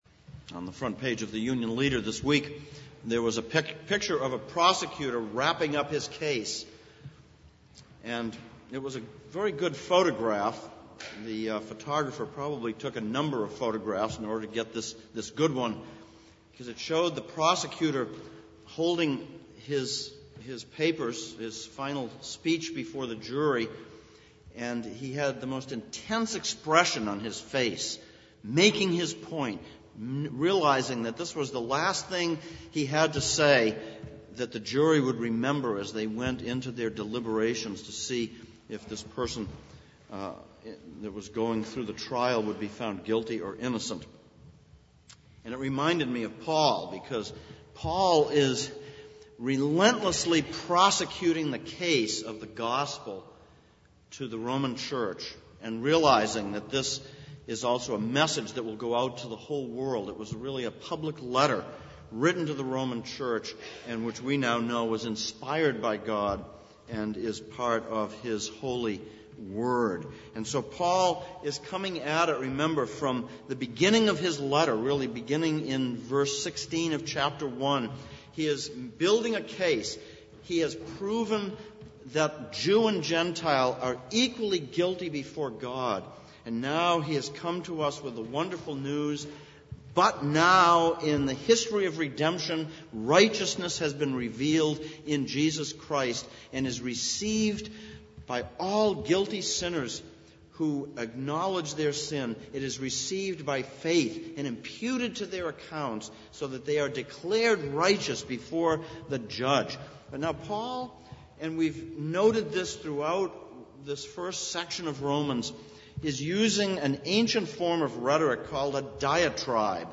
Series: Exposition of Romans Passage: Romans 3:21-31 Service Type: Sunday Morning « 13.